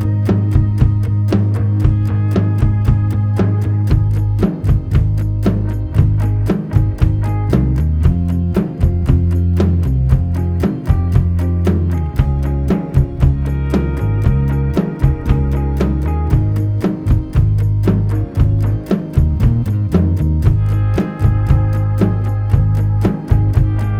Minus Guitars For Guitarists 3:04 Buy £1.50